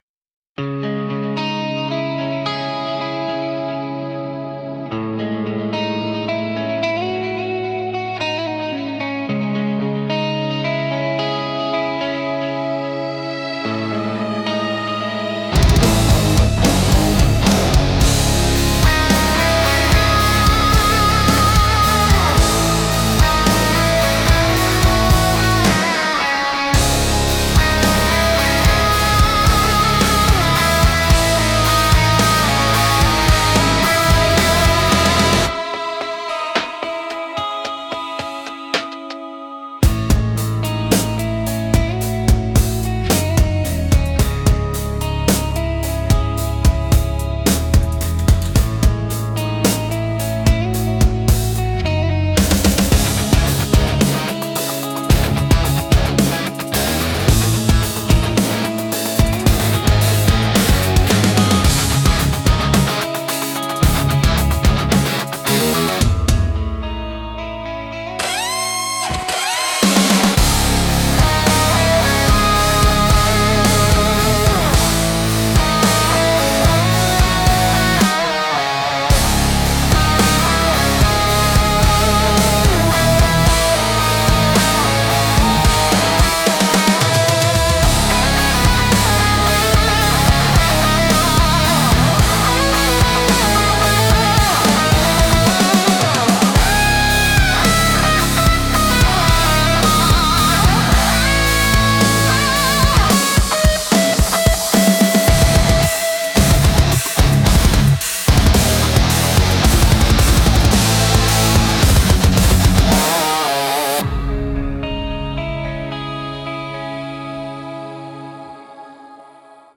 Instrumental - Midnight in the Middle of Nowhere